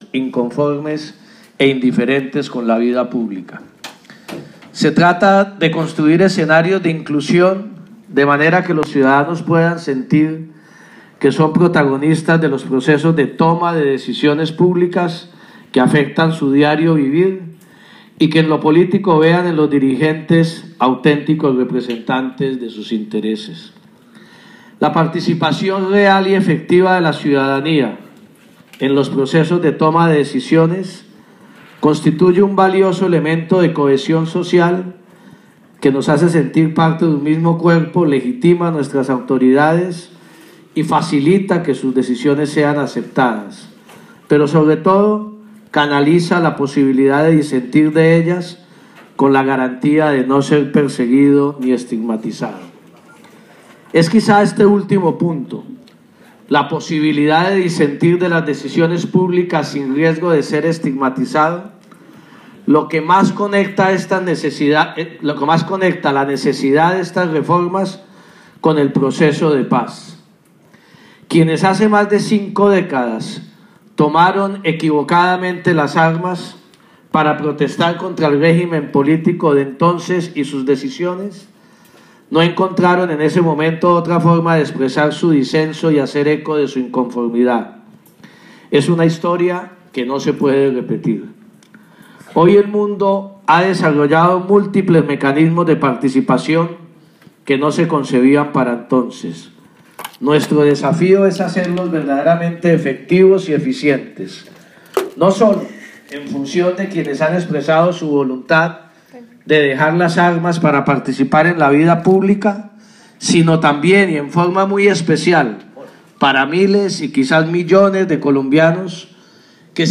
Al instalar el foro En La Ruta de la Paz: Diálogo Político, el ministro Juan Fernando Cristo insistió en que los cambios al sistema electoral, de partidos y el estatuto de la oposición son fundamentales para garantizar la inclusión de todos los movimientos sociales en la democracia.
foro-En-La-Ruta-de-la-Paz-Diálogo-Político.m4a